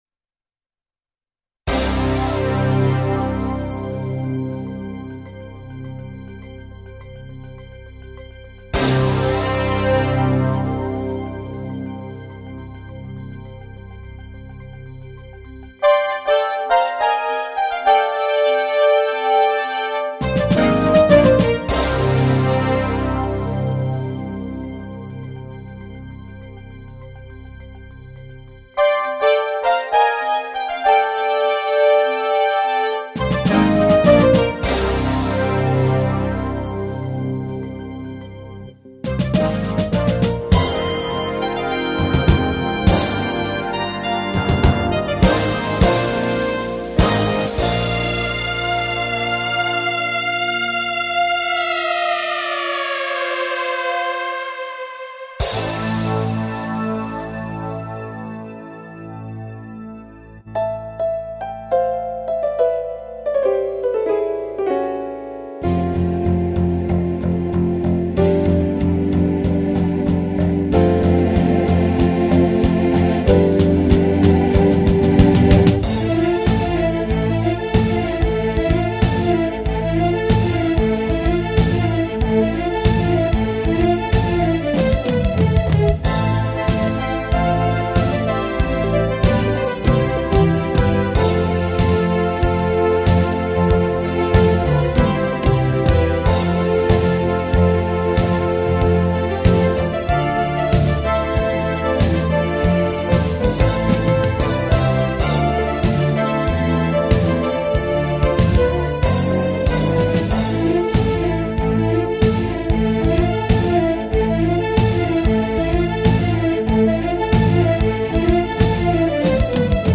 lab Yamaha Electone FX 20